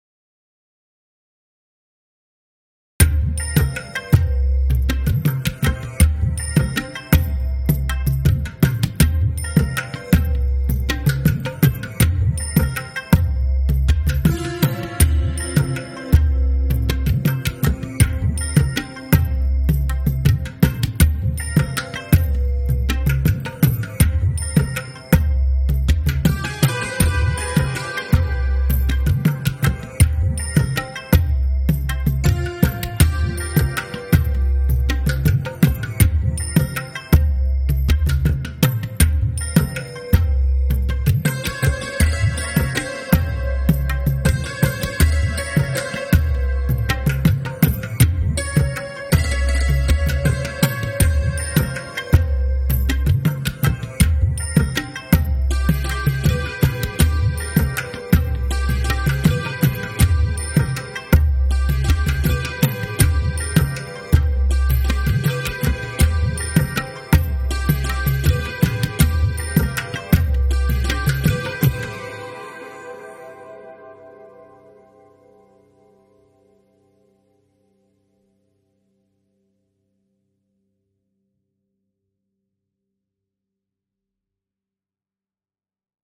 BGM
カントリーショート民族